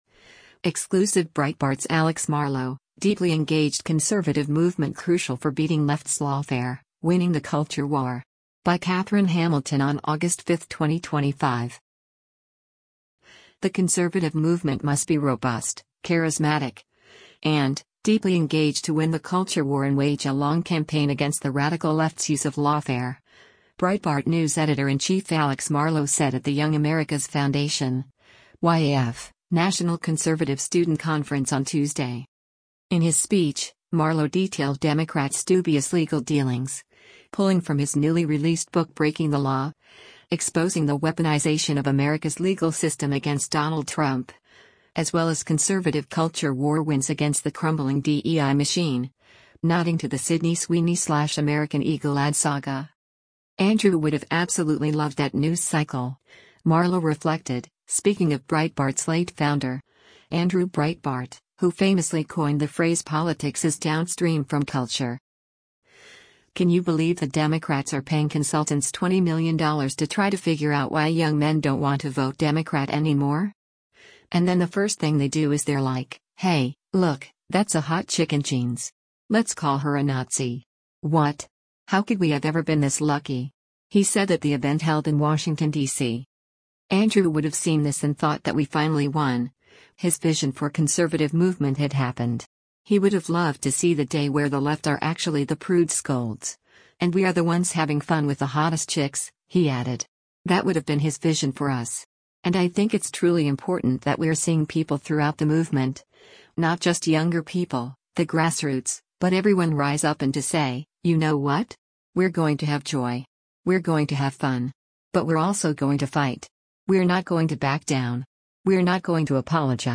In his speech
How could we have ever been this lucky?” he said at the event held in Washington, D.C.